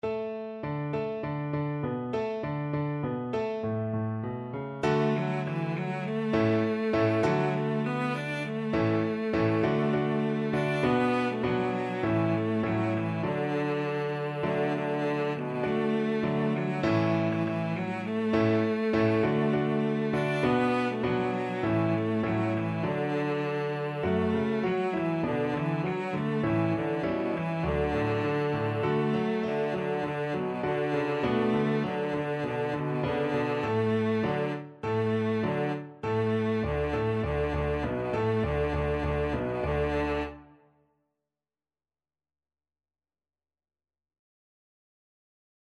Cello
D major (Sounding Pitch) (View more D major Music for Cello )
4/4 (View more 4/4 Music)
Joyfully =c.100
Traditional (View more Traditional Cello Music)
Chinese